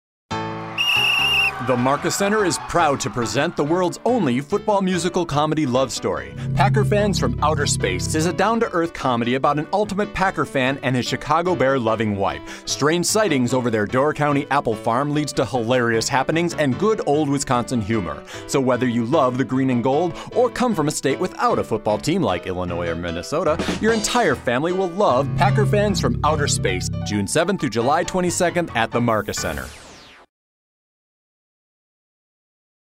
Packer Fans From Outer Space Radio Commercial